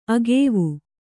♪ agēvu